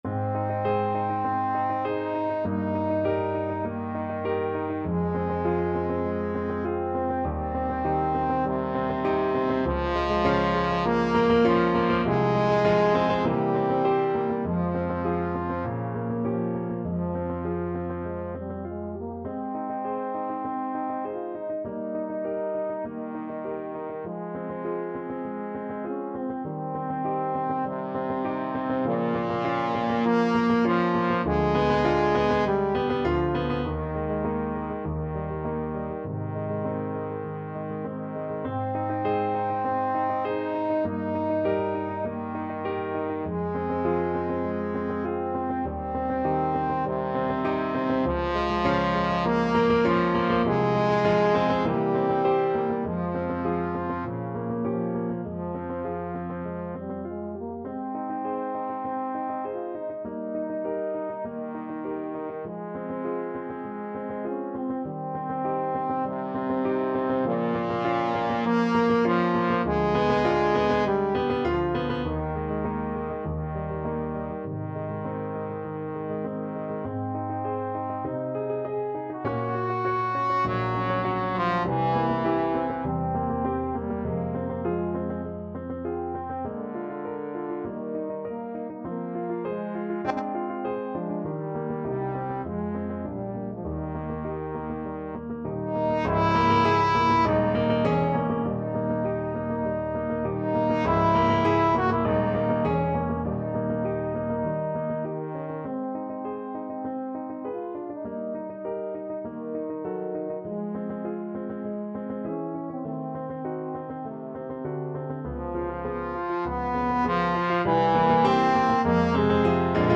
Allegro moderato (View more music marked Allegro)
Ab major (Sounding Pitch) (View more Ab major Music for Trombone )
4/4 (View more 4/4 Music)
Trombone  (View more Intermediate Trombone Music)
Classical (View more Classical Trombone Music)
dvorak_4_romantic_pieces_op75_1_TBNE.mp3